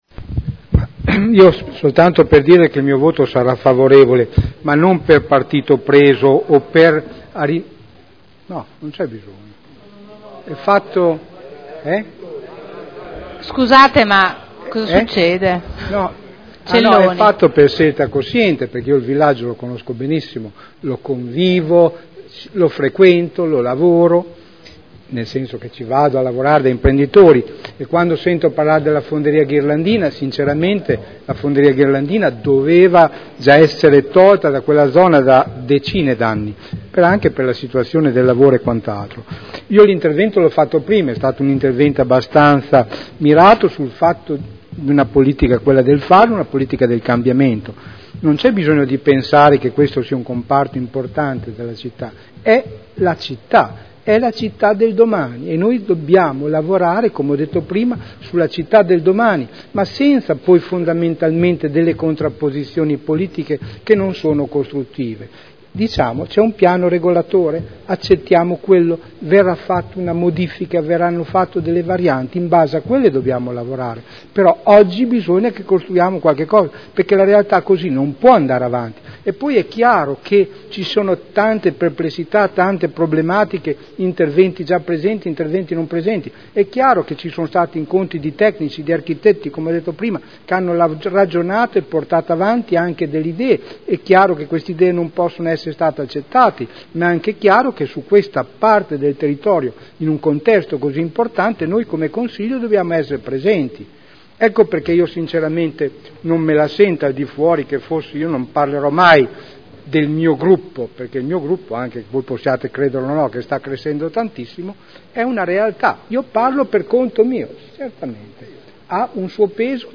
Seduta del 30 gennaio PROPOSTE DI DELIBERAZIONE POC di riqualificazione urbana del quadrante di Modena Ovest (POC MO.W) - Adozione. Dichiarazioni di voto